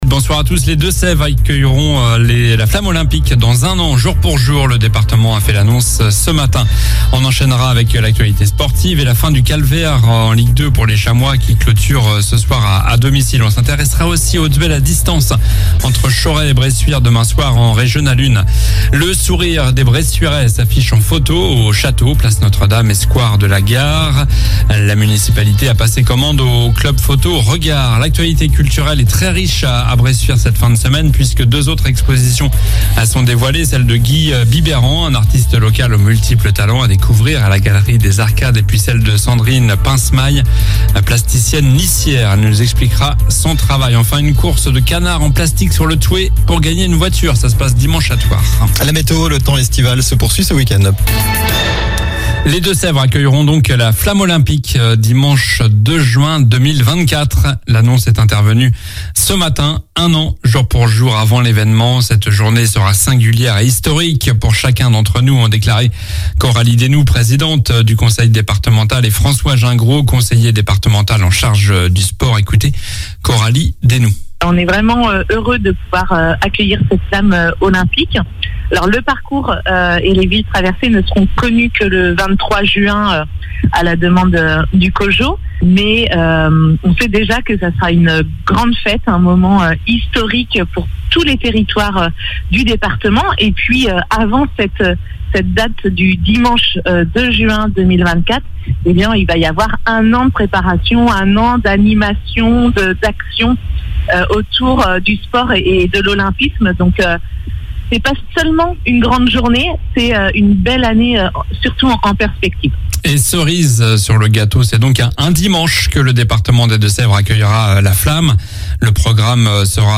Journal du vendredi 02 juin (soir)